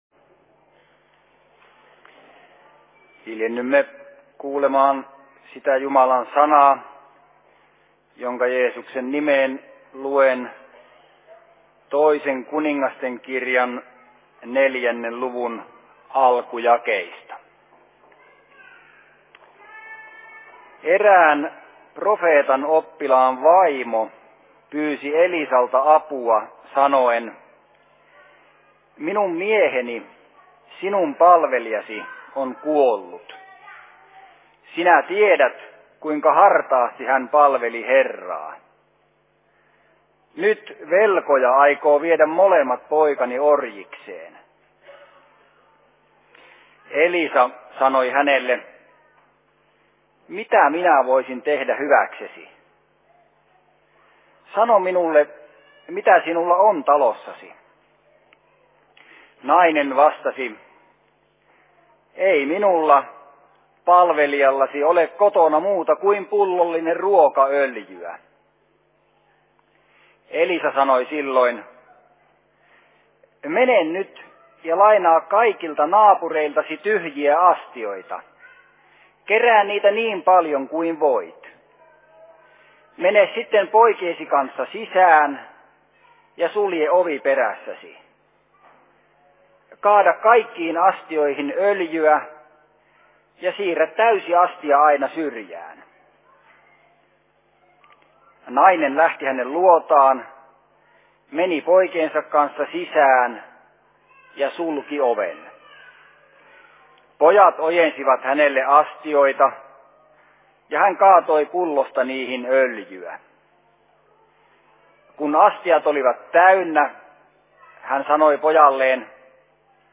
Syysseurat/Seurapuhe 05.10.2014
Paikka: Rauhanyhdistys Helsinki